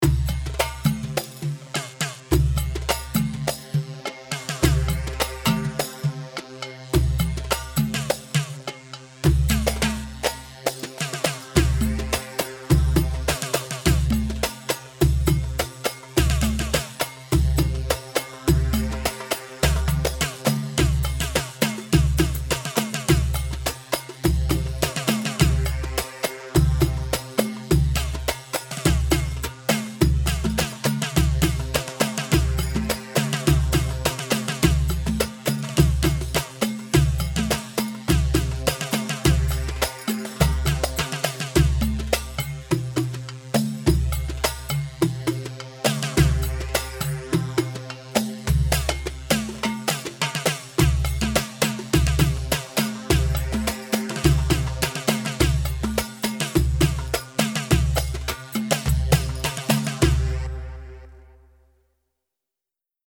Iraqi
Hewa C 4/4 104 هيوا